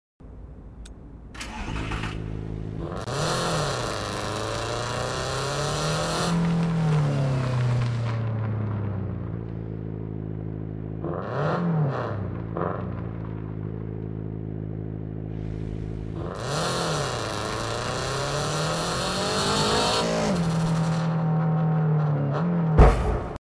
toyota gt86/subaru brz in car parking multiplayer 2 pure sound